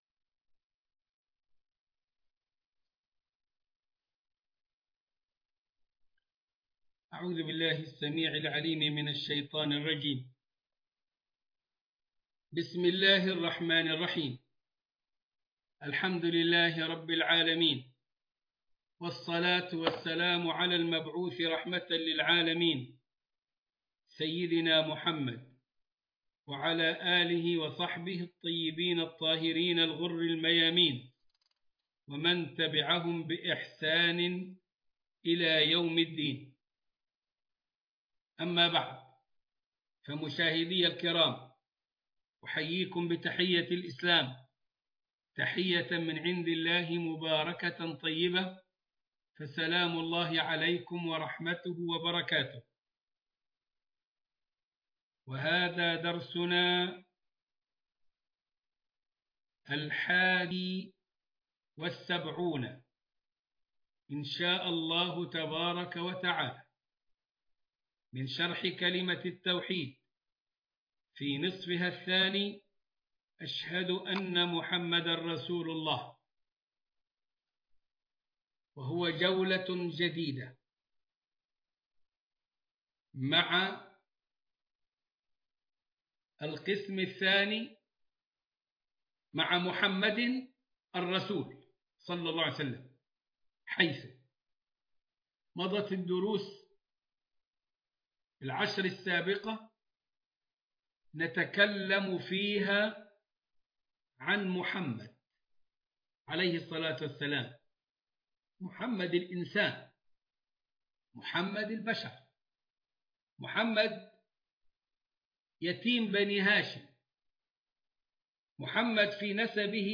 المحاضرة الحادية والسبعون